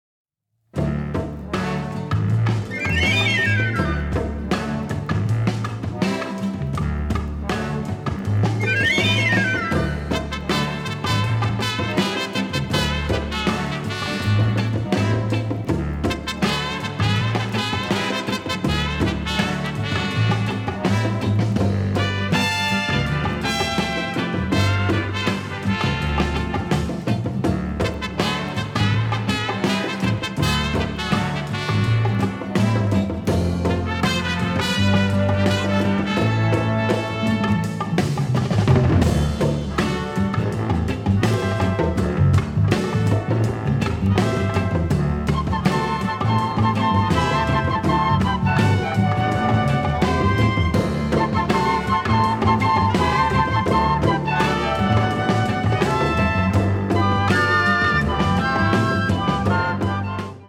an upbeat, jazzy style that establishes a light vibe